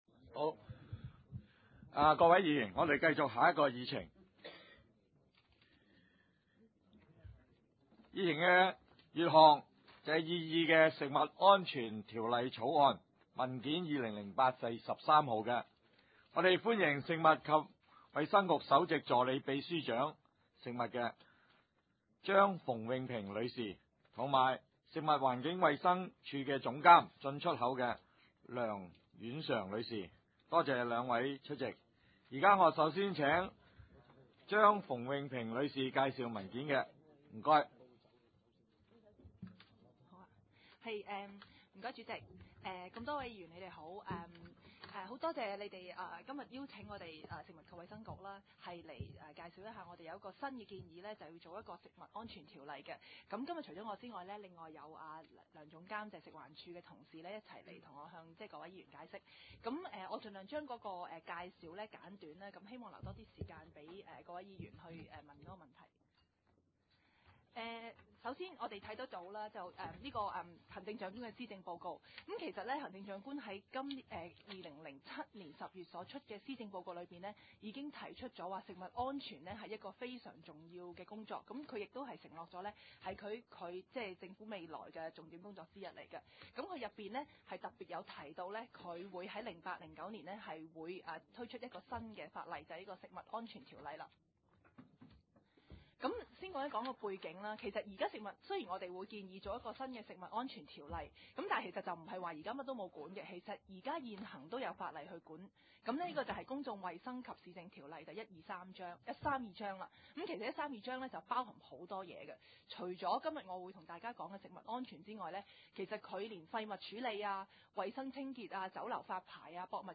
北區區議會第22次會議紀錄